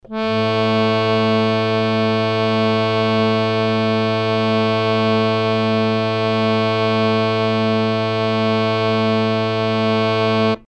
interactive-fretboard / samples / harmonium / A2.mp3